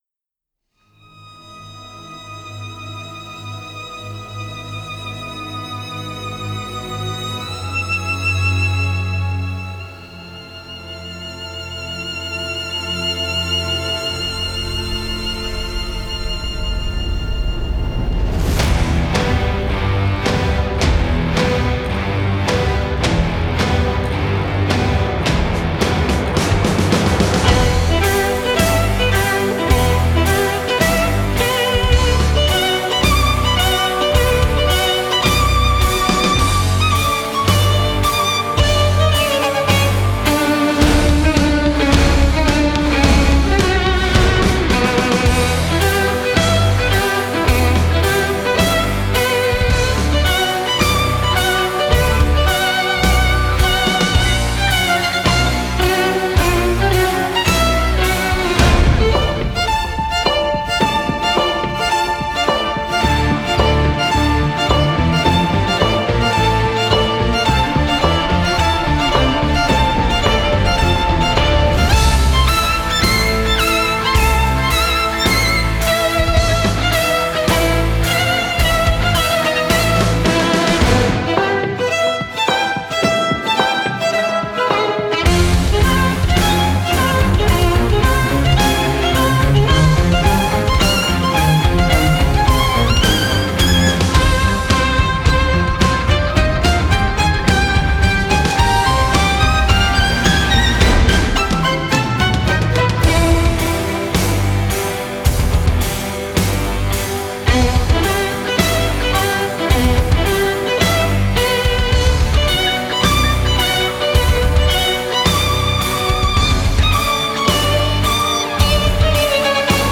Genre: Score
virtuoses Spiel mit modernen Klängen